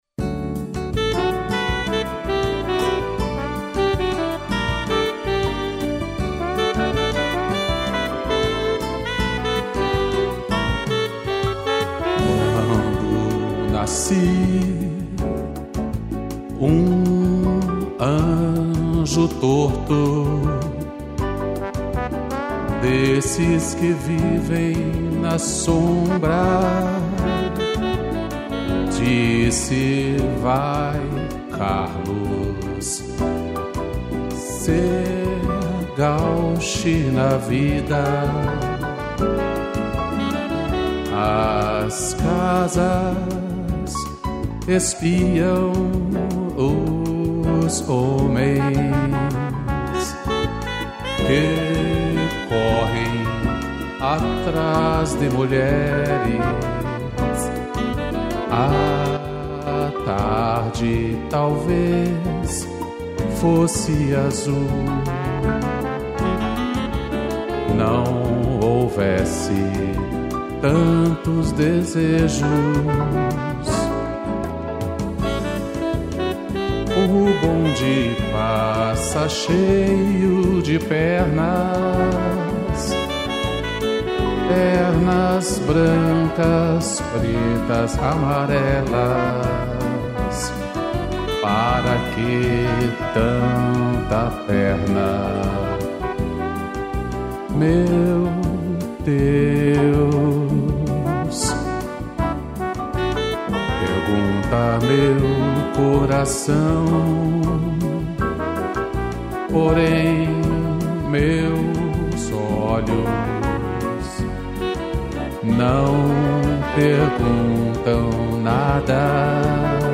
piano, sax, trombone e strings